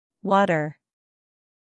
General American: Rhotic /r/ everywhere, /æ/ tenser in “dance.”
water-American.mp3